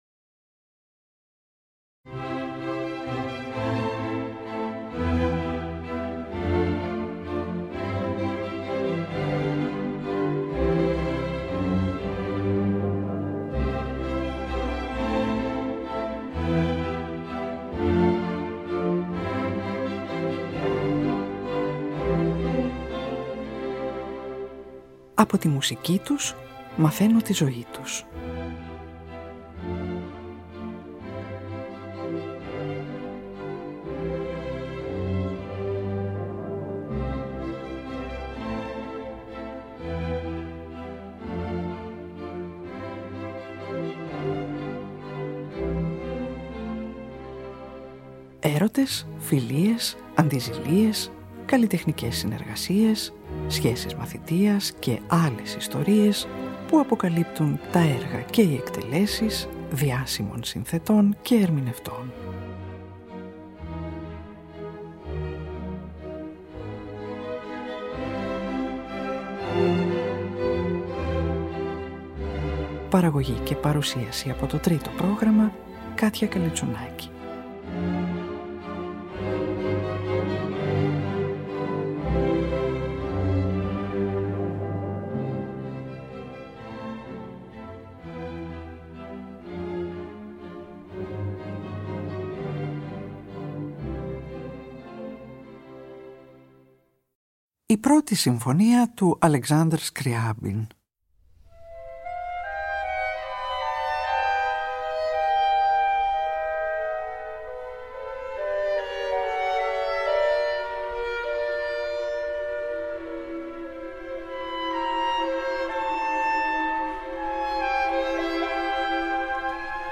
για μετζοσοπράνο, τενόρο και χορωδία
από ηχογράφηση του 1994